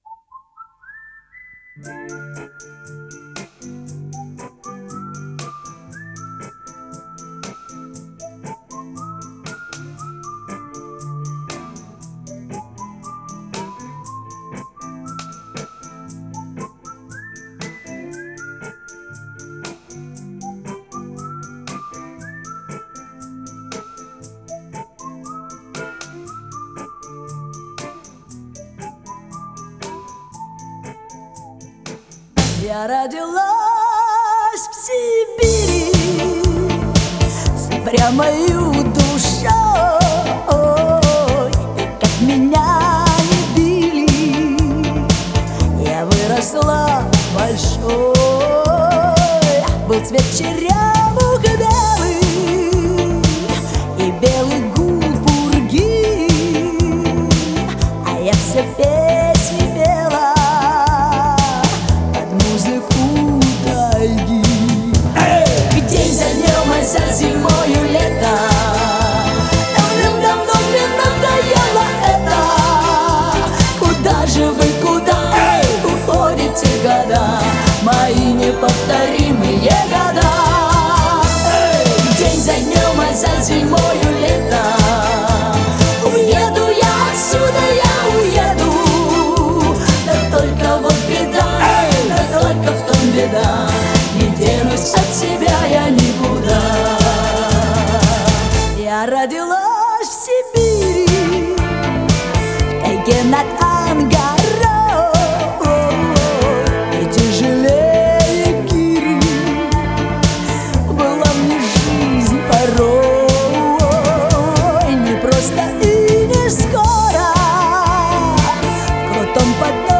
российская поп-певица